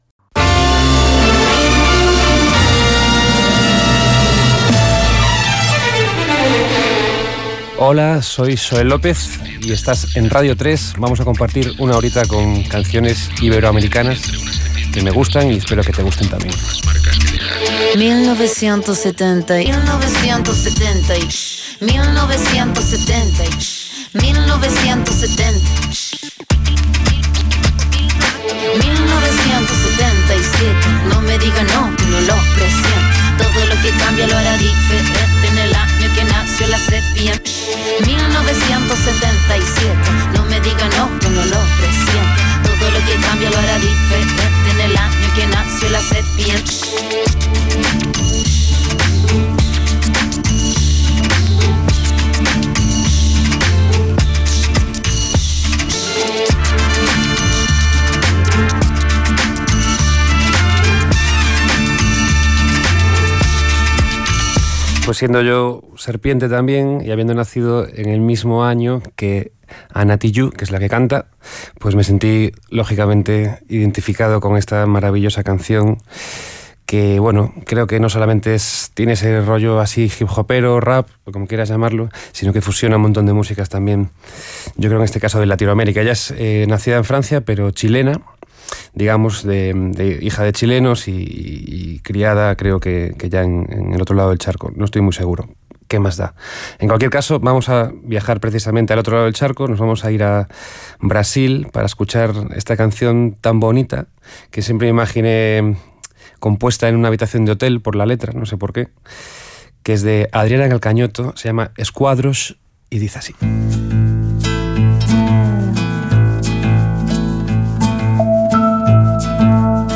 Presentació, tema musical, comentari del que s'ha escoltat i presentació del següent tema Gènere radiofònic Musical